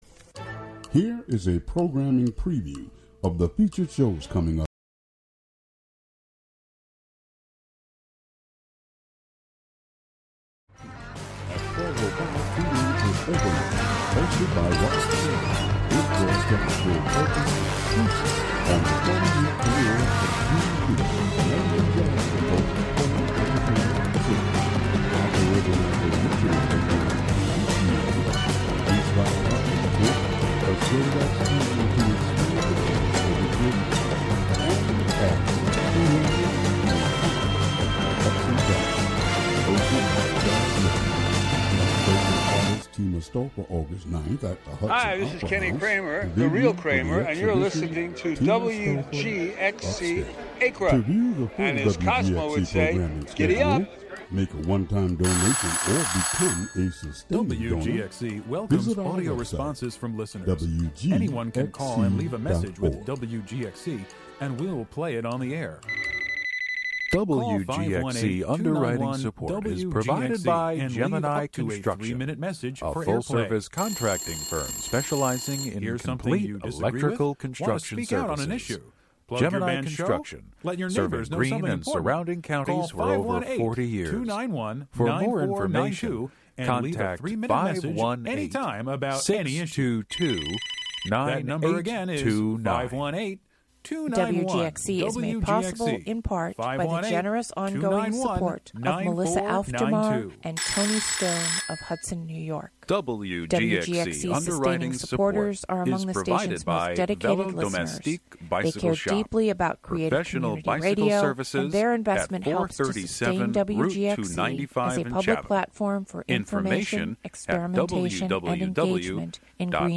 Broadcast live HiLo in Catskill.